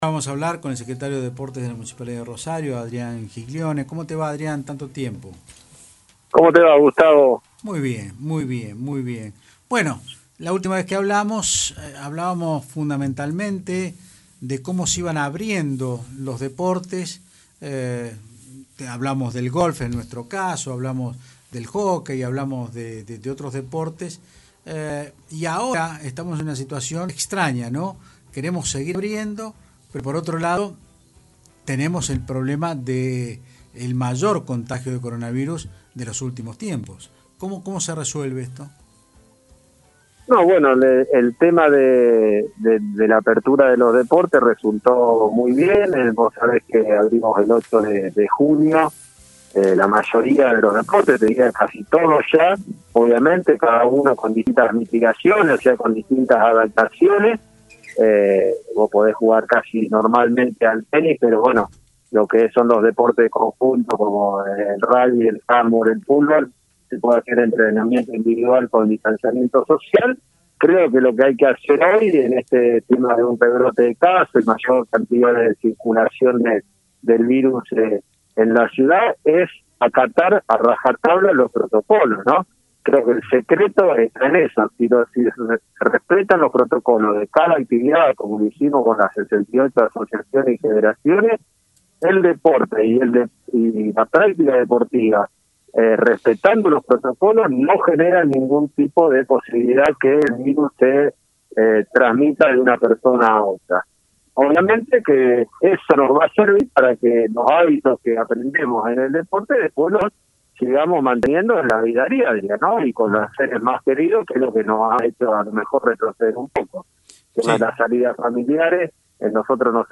El secretario de Deportes de la Municipalidad Adrián Ghiglione dijo en Otros Ámbitos (Del Plata Rosario 93.5) como Rosario tenía pocos casos se flexibilizó algunas prácticas que no se podían y se dieron casos como el de Gimnasia y Esgrima pero ya se está trabajando para corregir lo que pasó.